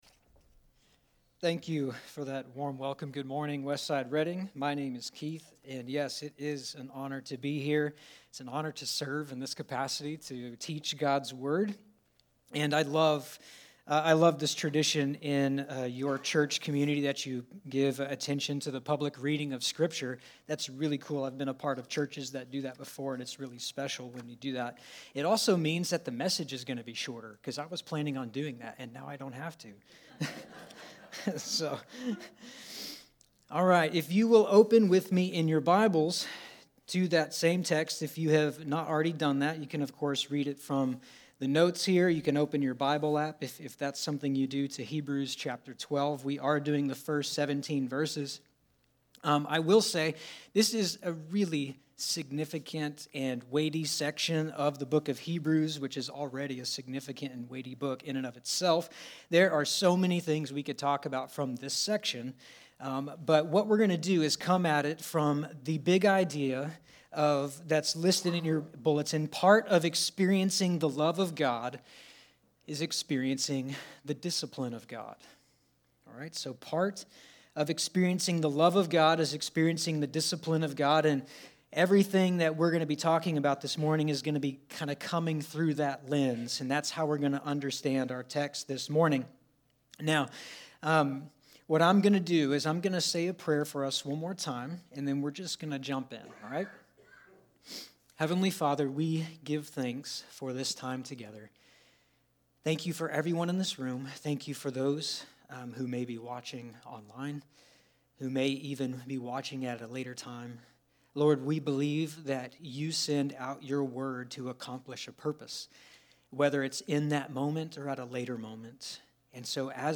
02/11/2024 No Greater Love Than This: The Discipline of God Series: Great Commission Month Passage: Hebrews 12:1-17 Service Type: Special Sermons Part of experiencing the love of God is experiencing the discipline of God.